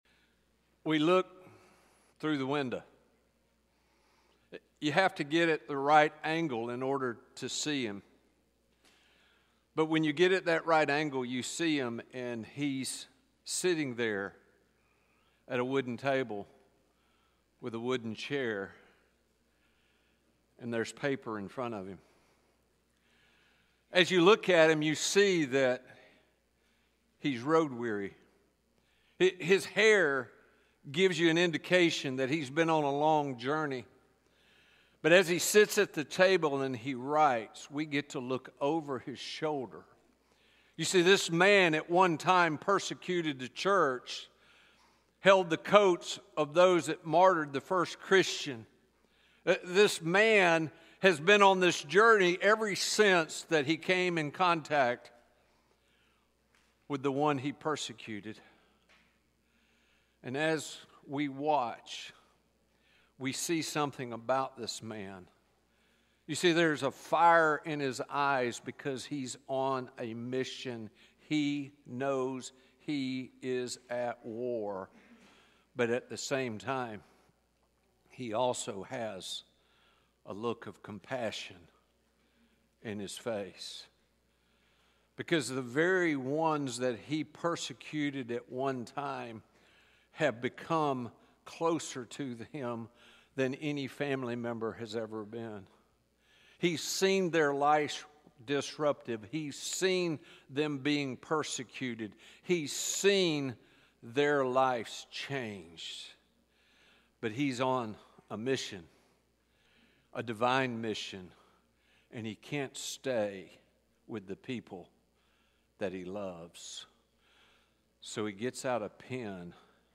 Recording from North Tampa Church of Christ in Lutz, Florida.